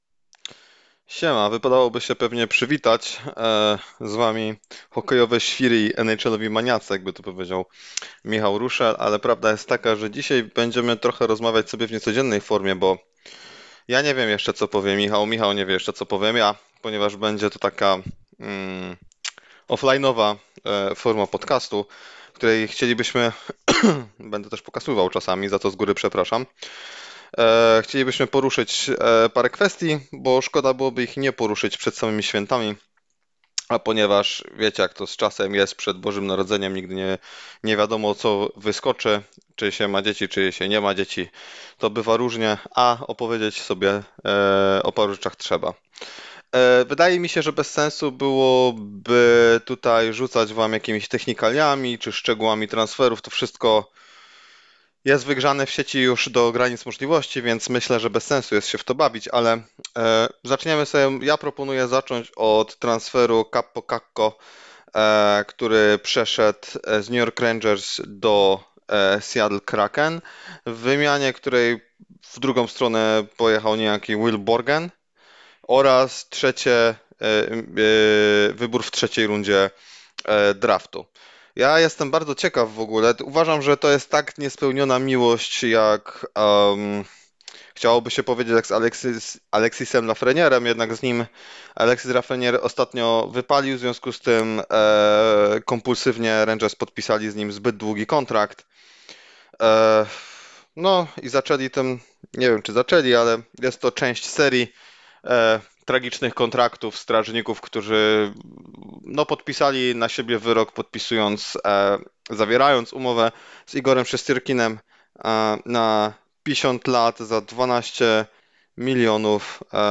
W formie odrębnie nagranych monologów